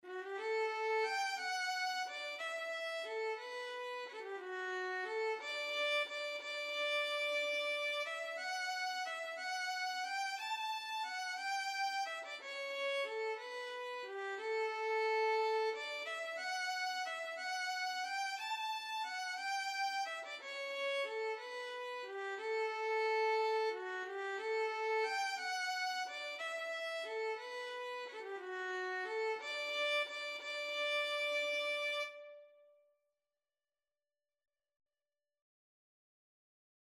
3/4 (View more 3/4 Music)
D major (Sounding Pitch) (View more D major Music for Violin )
Violin  (View more Intermediate Violin Music)
Traditional (View more Traditional Violin Music)
Irish